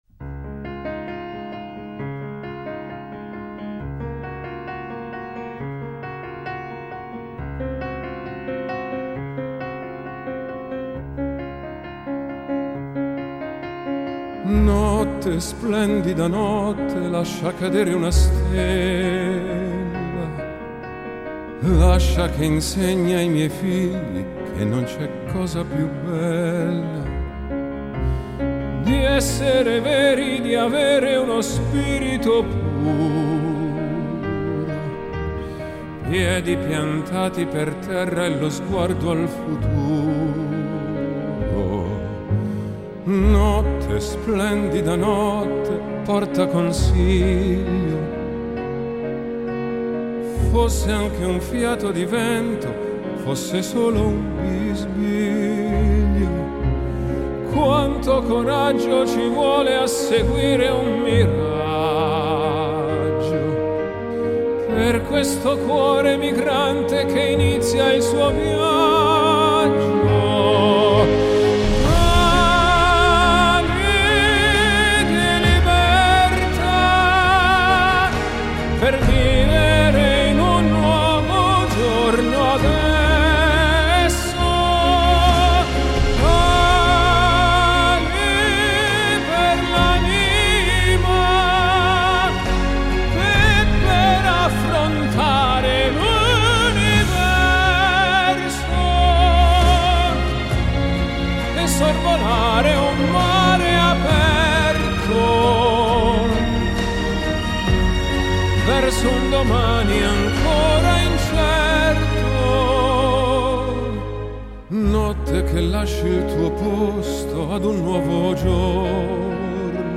Classical, Pop, Operatic Pop